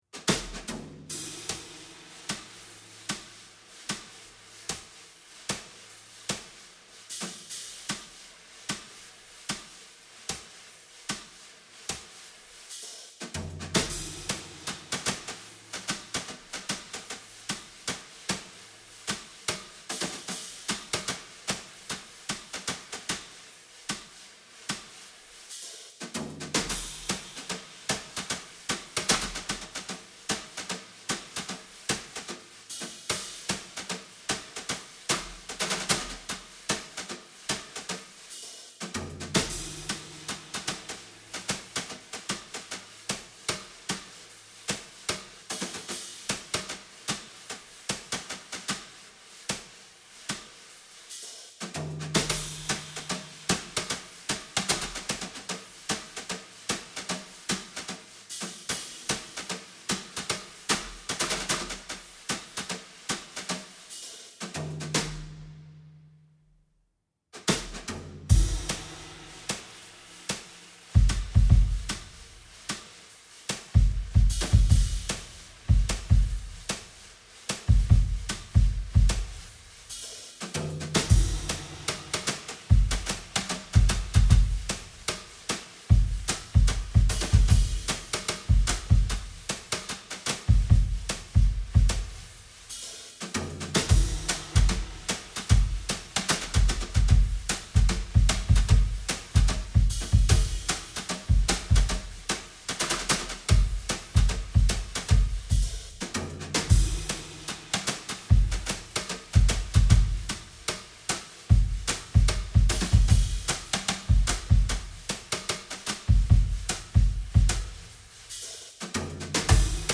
drums only